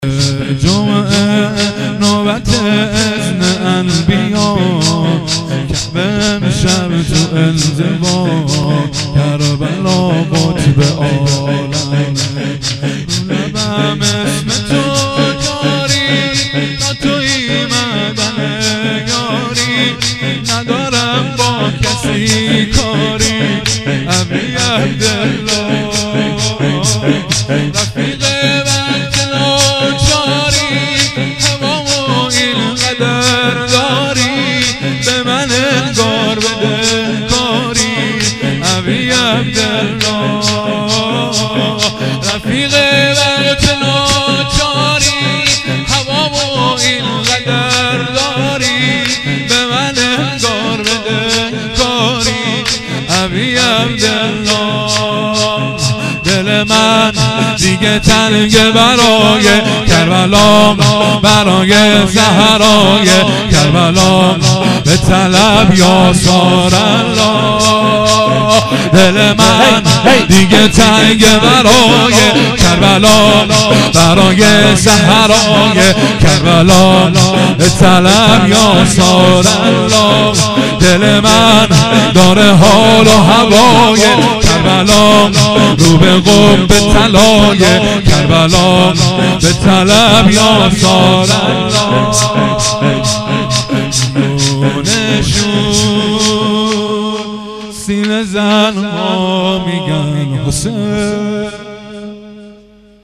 فایل های صوتی مراسم هفتگی مرداد 94
haftegi-22-mordad-94-shor5.mp3